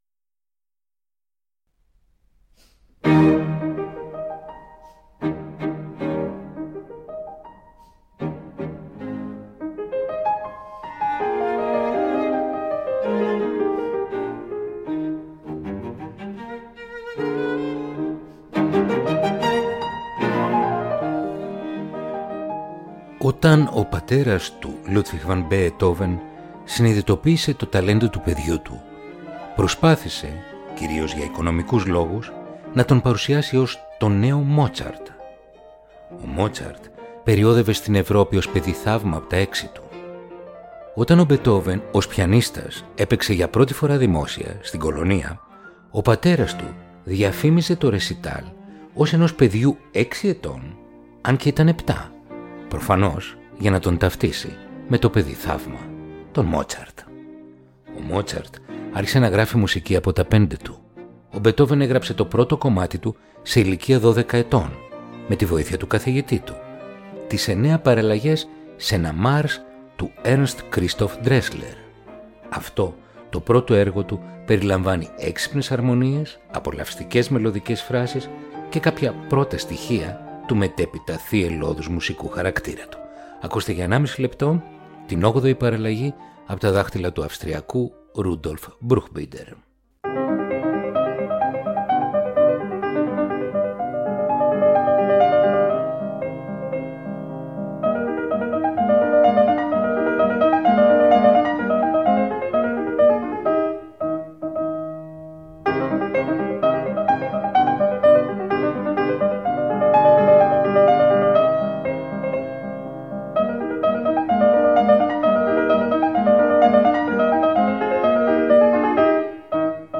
Ludwig van Beethoven Κοντσέρτο για Πιάνο και Ορχήστρα Αρ. 2 σε Σι Ύφεση Μείζονα